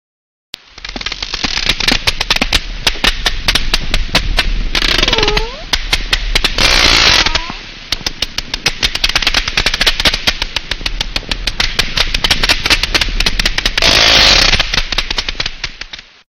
На этой странице собраны их уникальные вокализации: от низкочастотных стонов до резких щелчков эхолокации.
кашалот в процессе охоты на добычу